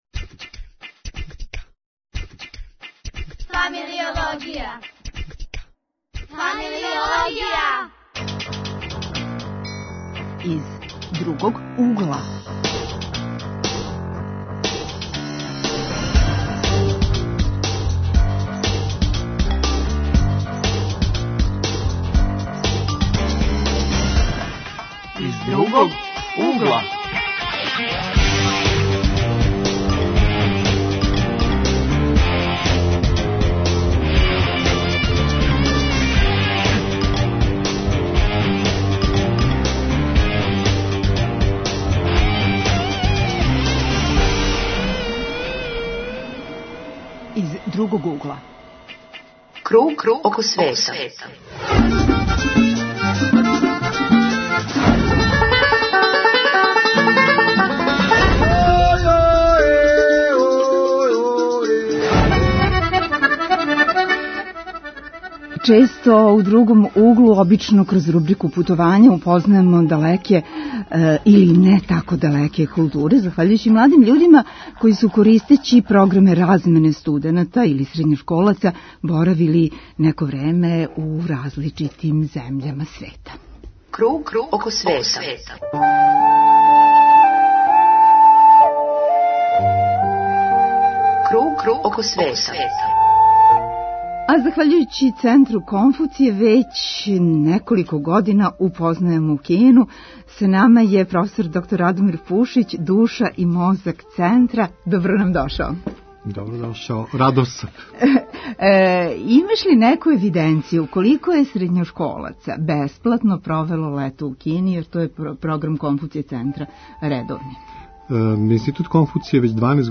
Гости: студенти